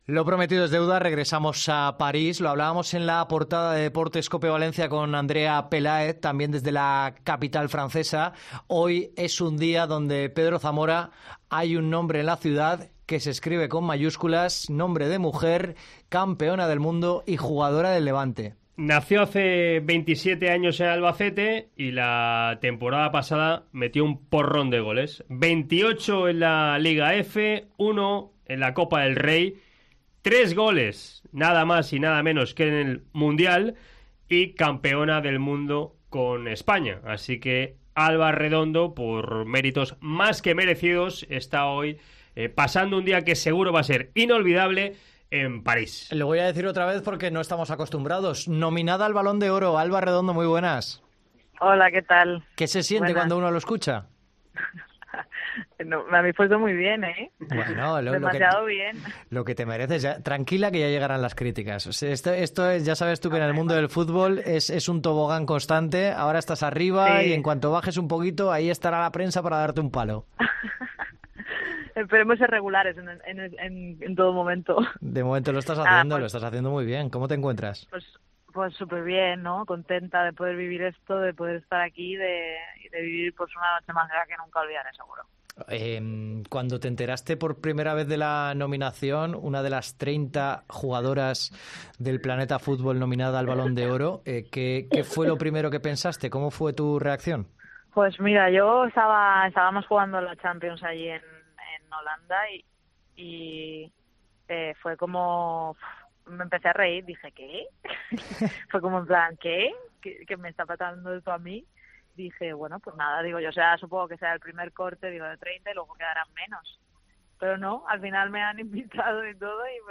La jugadora del Levante UD atiende desde París a Deportes COPE Valencia. Alba Redondo es una de las nominadas al Balón de Oro y ha hecho historia...